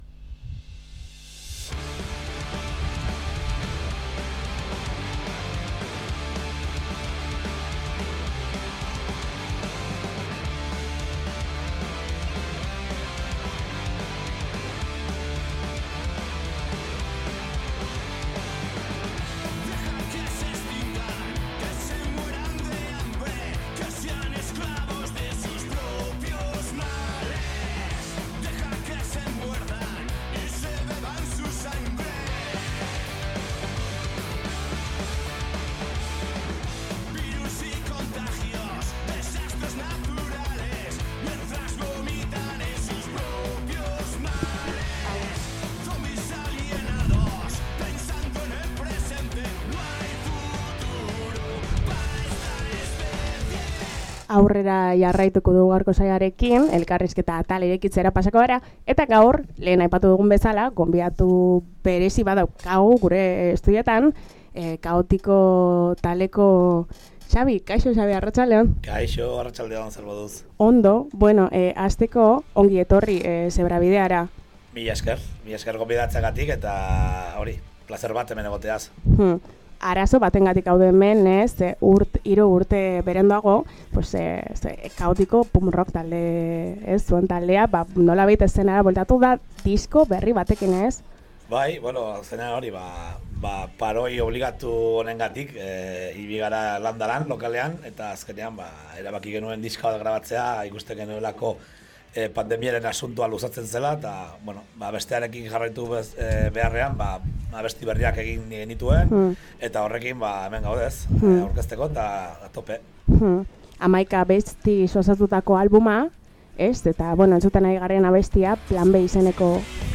Boskote arabarraren esentzia hurbiltzeko urte hauetaz izandako ibildideaz, datozer kontzertuen dataz eta bestelako gaiaz aritu gara Halabediko estudioan.